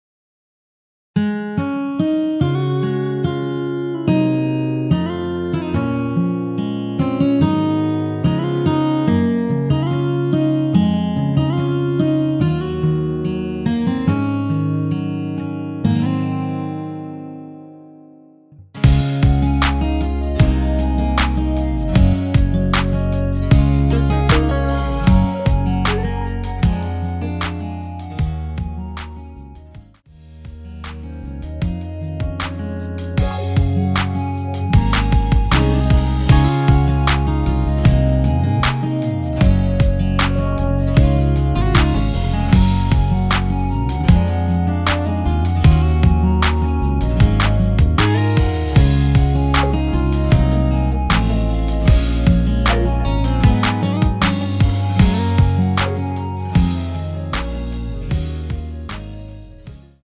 원키에서(-2)내린 (1절+후렴)멜로디 포함된 MR입니다.(미리듣기 확인)
Db
앞부분30초, 뒷부분30초씩 편집해서 올려 드리고 있습니다.
중간에 음이 끈어지고 다시 나오는 이유는